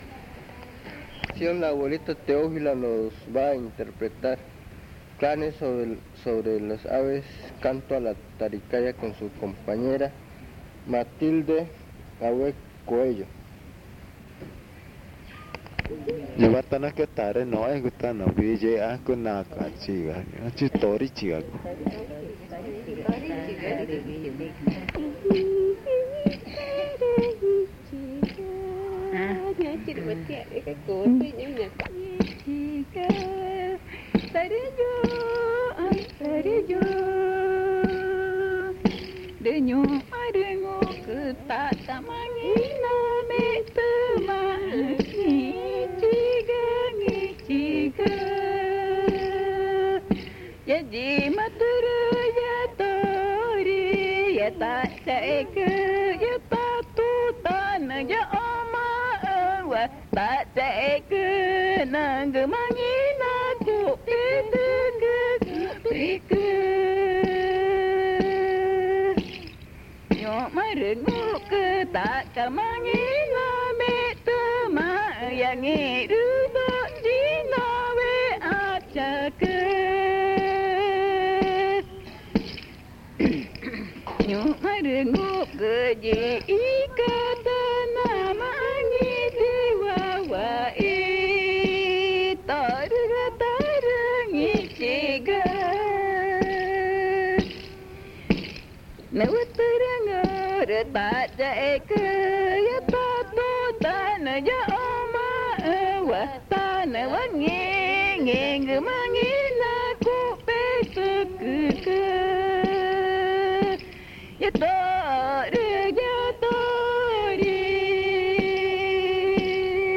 Pozo Redondo, Amazonas (Colombia)
En la canción se habla de la muchacha (worekü), del canto y el baile que se le realiza, de su clan, su nación, el nombre que se le ha otorgado y los consejos que se le brindan. Las abuelas utlizan el tambor durante su canto.
The elders use the drum during their performance.